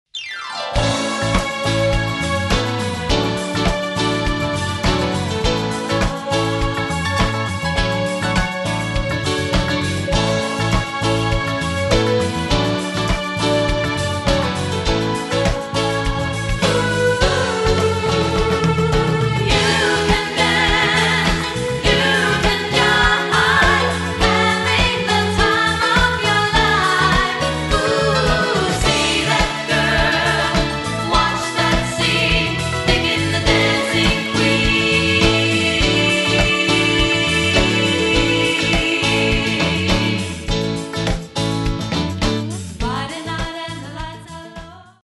KOPI-ORKESTRE
De 7 professionelle showmusikere
velspillede live-udgaver